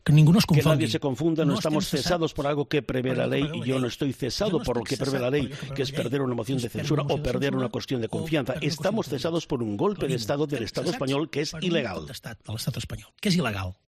En una entrevista concedida a Catalunya Radio desde Bélgica, Puigdemont ha indicado que los exmiembros del Govern que se encuentran en ese país "en ningún momento hemos eludido ninguna responsabilidad" y ha resaltado que se presentaron "voluntariamente ante la justicia belga" tras la euroorden de detención dictada por la Audiencia Nacional.